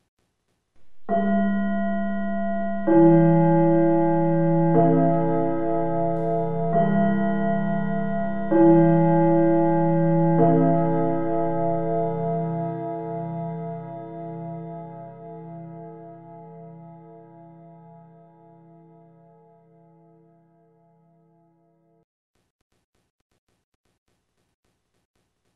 Schulgong Dreiklang
Türklingel Klingelton Schulklingel Gong
Kategorie: Glockenpfeifen Geräusche
Der charakteristische Dreiklang, der nach unten absteigt, ist ein unverwechselbares Element jeder Schulatmosphäre. Ideal für alle, die den authentischen Klang der Schulglocke oder -gong nachahmen möchten.
schulgong-dreiklang-id-www_tiengdong_com.mp3